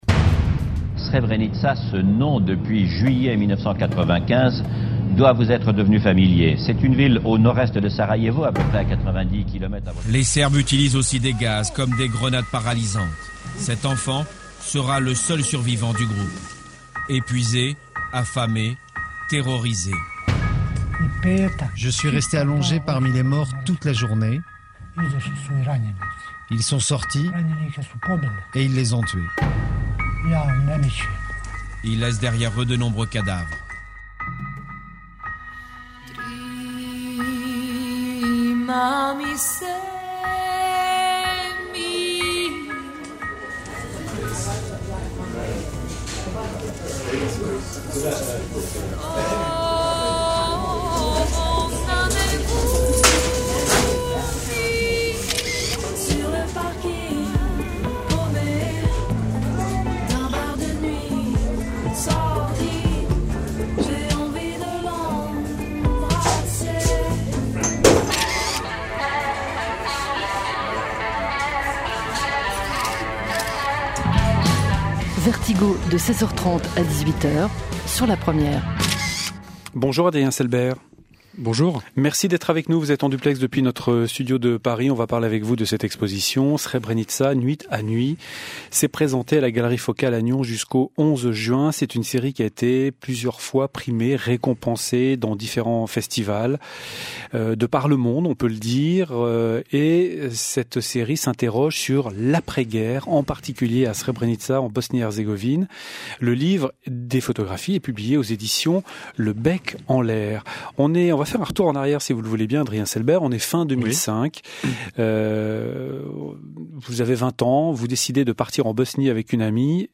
invité de l’émission Vertigo de la RTS